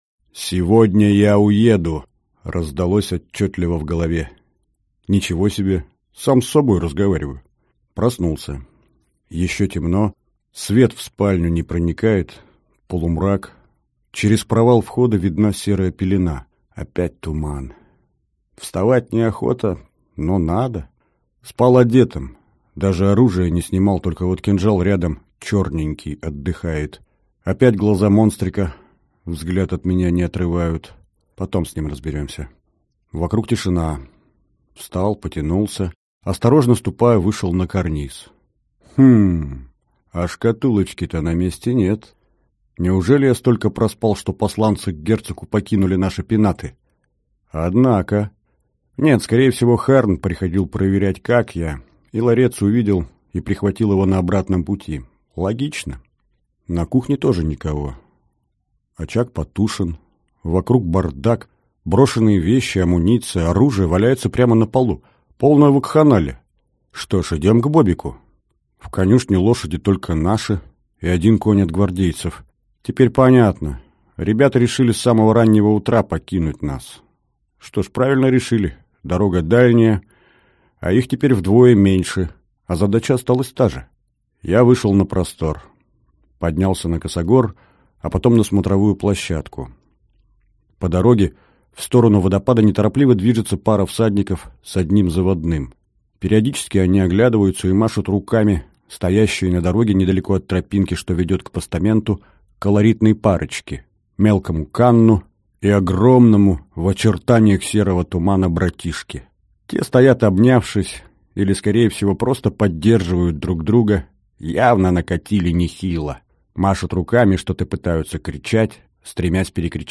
Аудиокнига Малыш Гури. Книга вторая. Мы в ответе за тех…